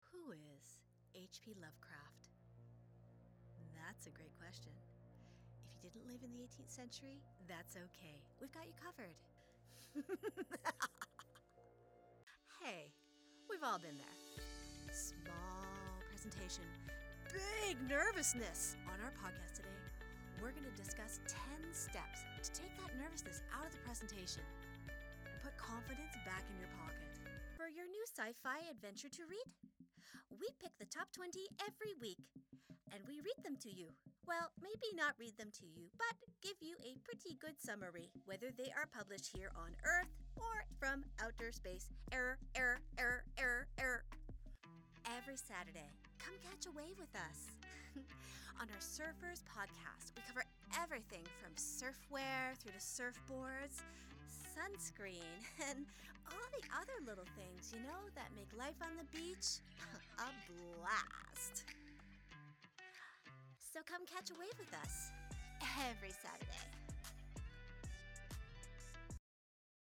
Podcast Voice Over Talent | Professional Voice for Podcast
My voice is ideal for advertising, audiobooks, I.V.R., e-learning, podcast and storytelling. Institutional, inspirational, compelling, educated, smooth, formal....